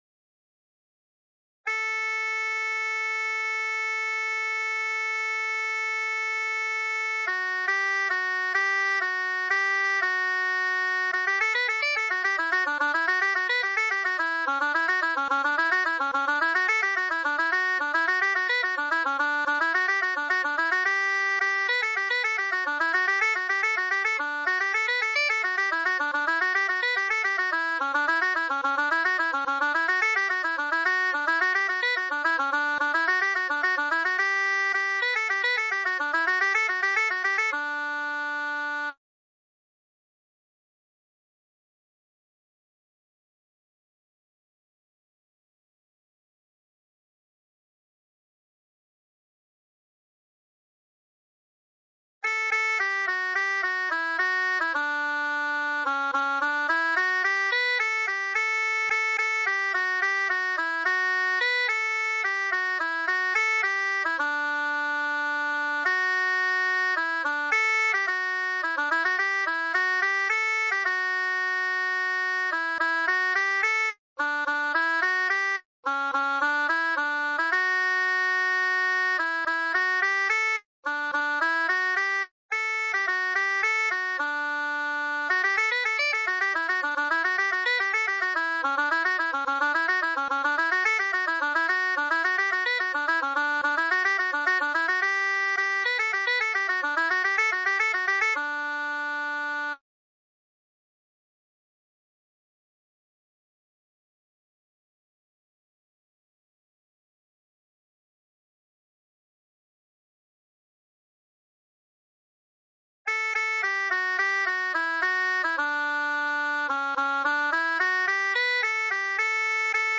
Segunda voz